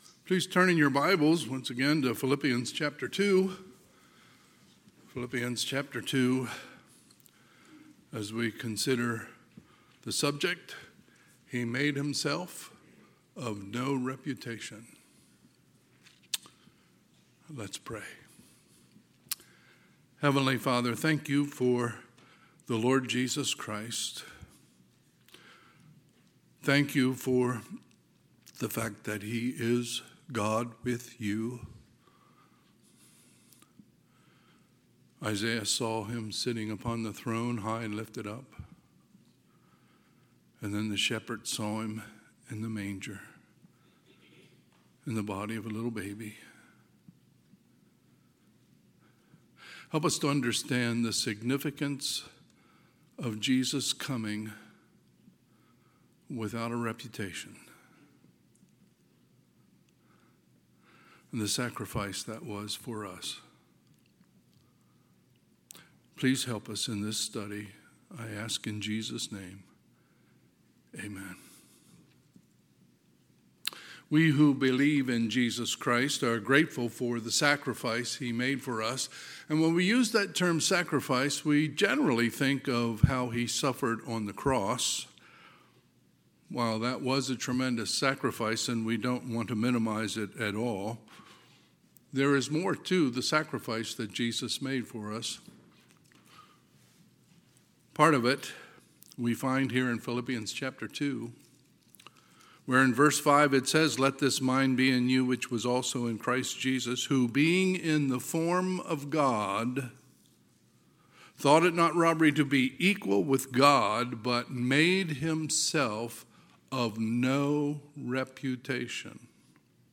Sunday, December 7, 2025 – Sunday AM
Sermons